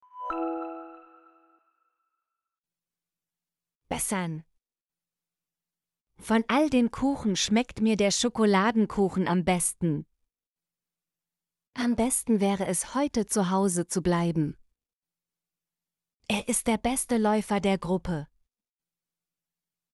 besten - Example Sentences & Pronunciation, German Frequency List